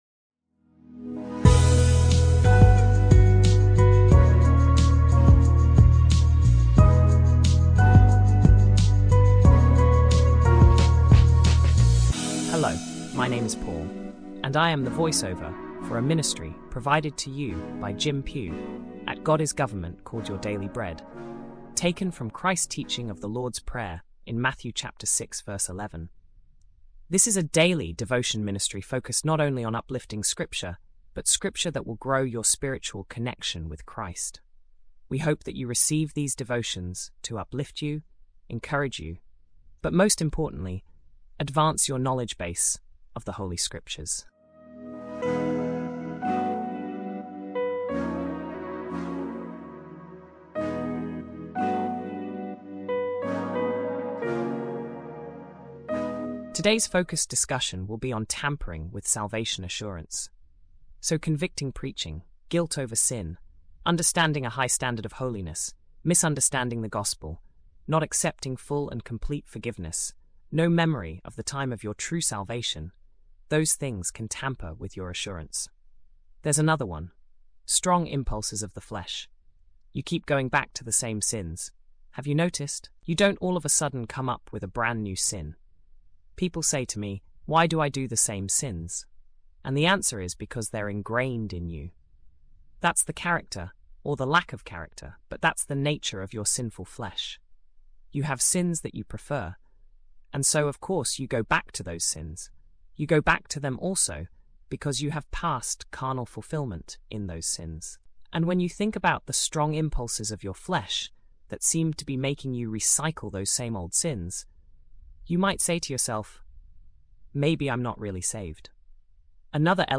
This talk